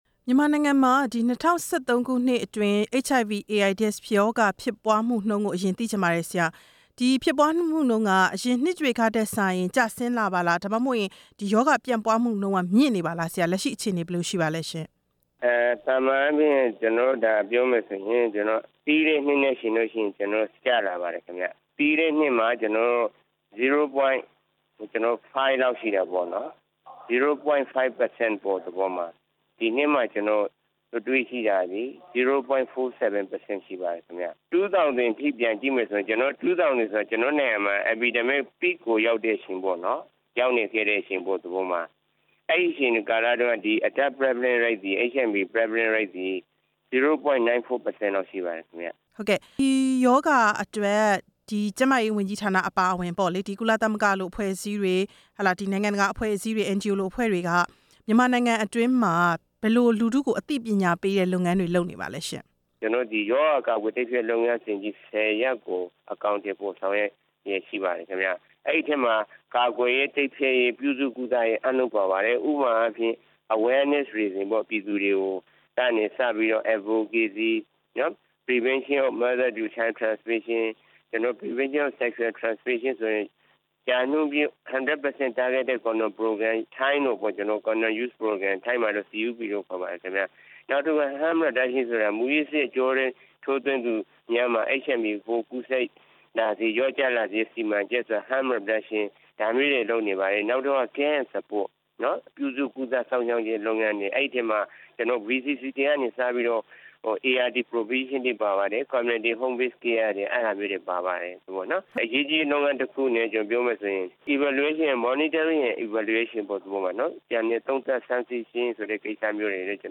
ကမ္ဘာ့ AIDS နေ့ ကျန်းမာရေးဝန်ကြီးဌာန အရာရှိနဲ့ ဆက်သွယ်မေးမြန်းချက်